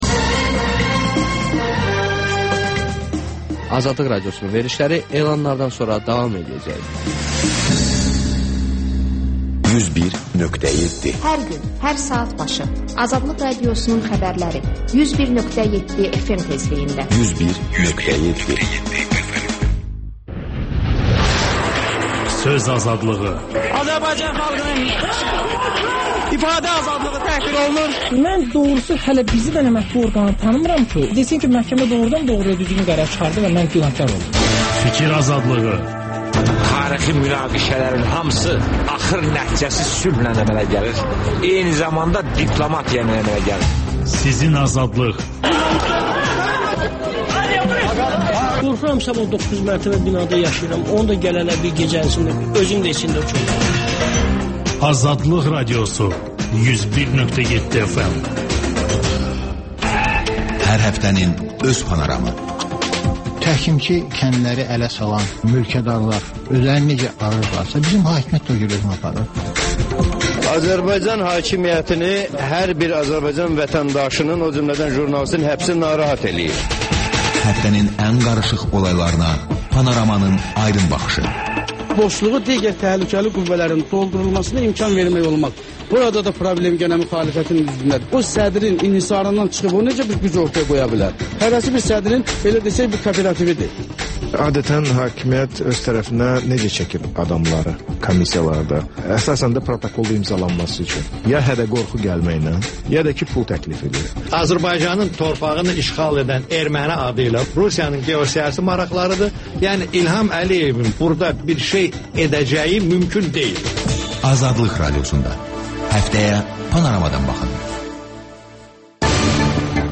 Xəbərlər, HƏMYERLİ: Xaricdə yaşayan azərbaycanlılar haqda veriliş, sonda MÜXBİR SAATI